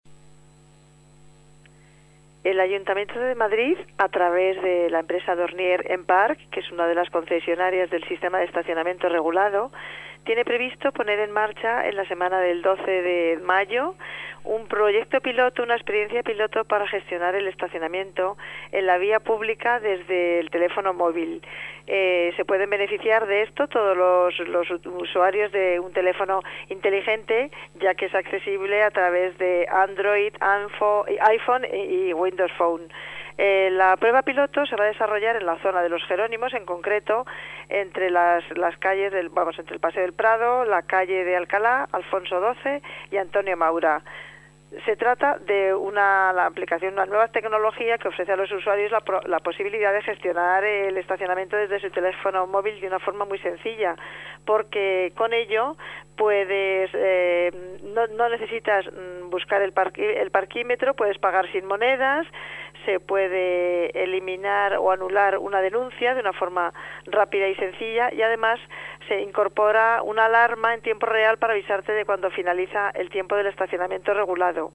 Nueva ventana:Declaraciones de Elisa Bahona, directora general de Sostenibilidad del Área de Medio Ambiente